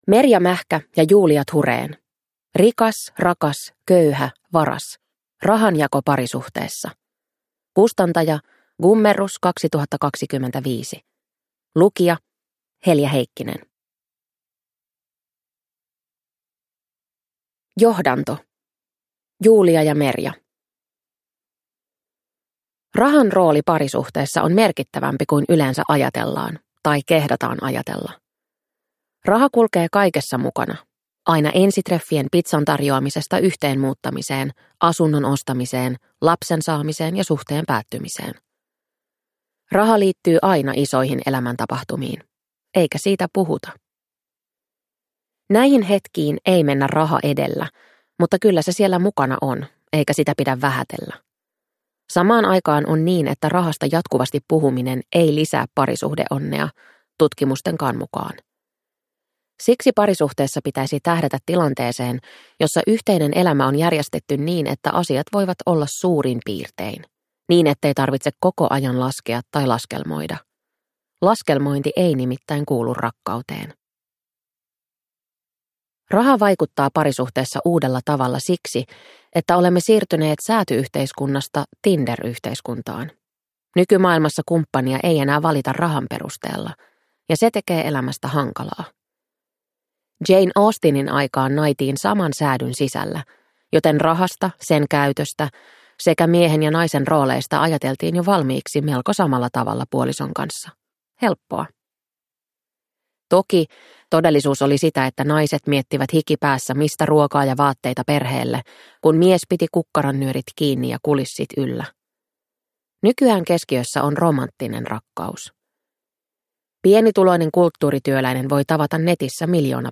Rikas, rakas, köyhä, varas – Ljudbok